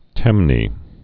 (tĕmnē)